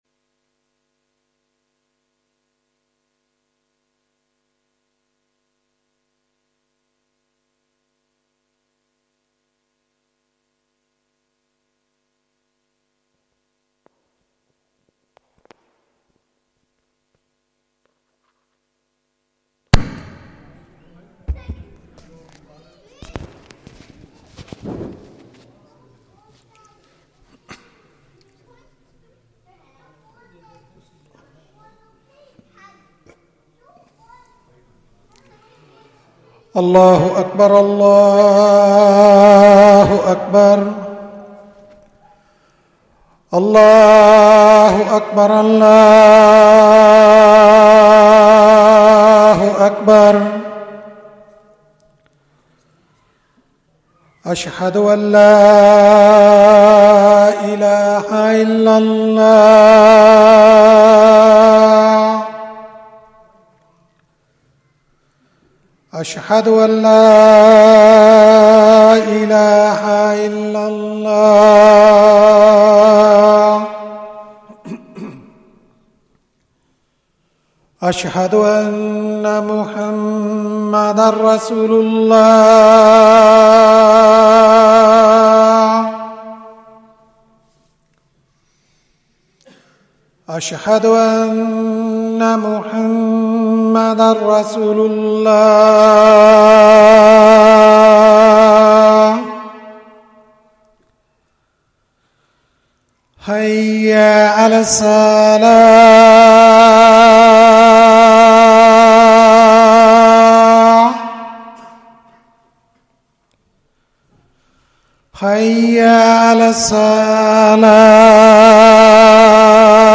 Zakariyya Masjid Motherwell